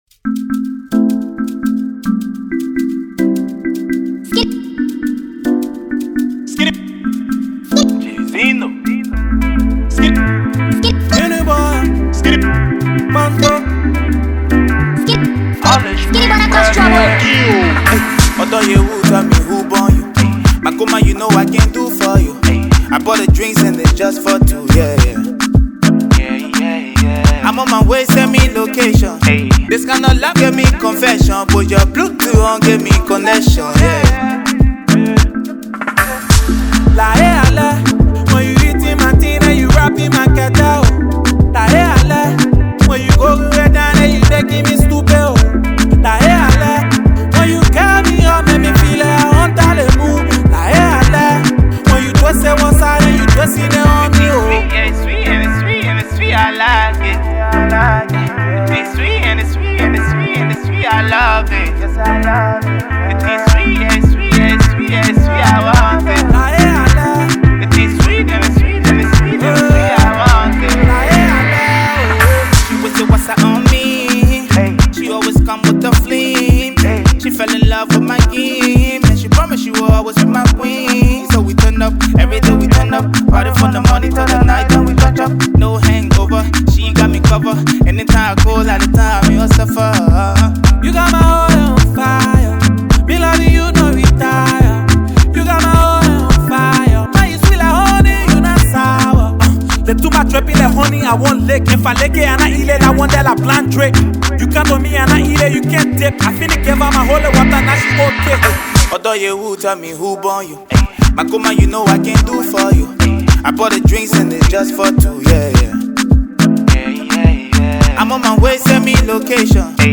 a burst of positive energy that can uplift your spirit
a feel-good anthem